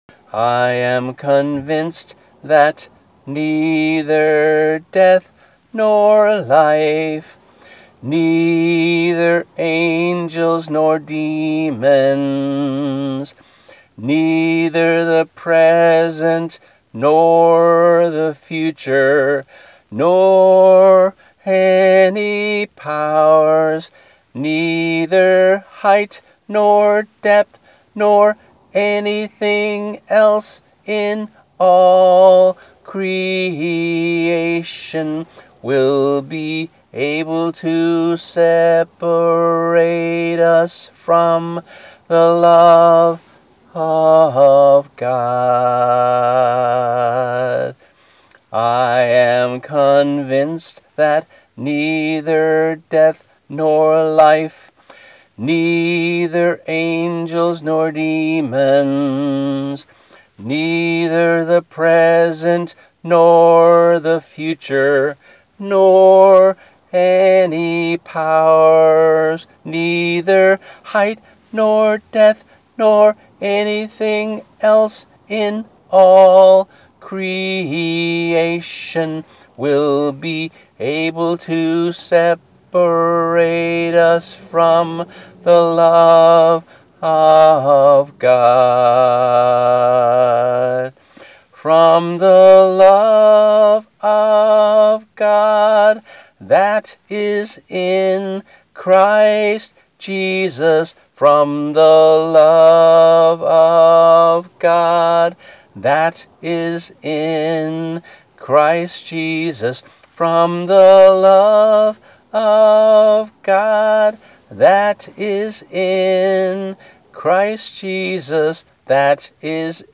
MP3 - voice only